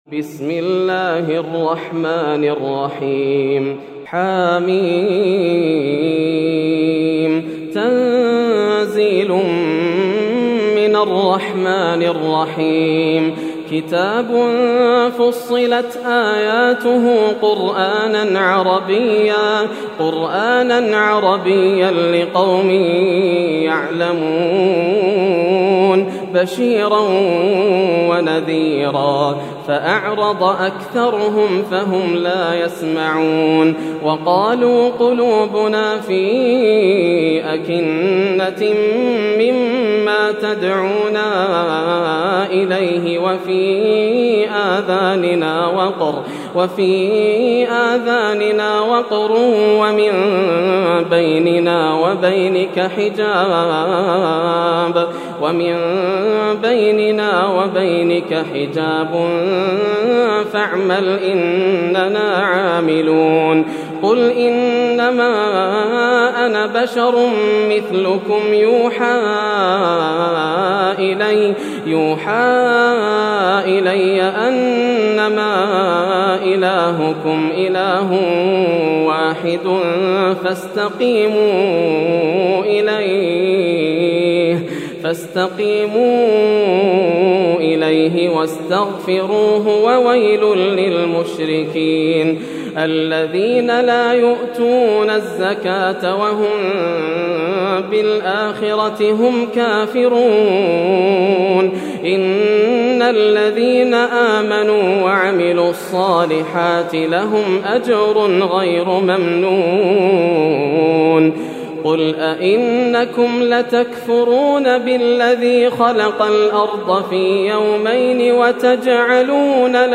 سورة فصلت > السور المكتملة > رمضان 1431هـ > التراويح - تلاوات ياسر الدوسري